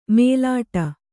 ♪ mēlāṭa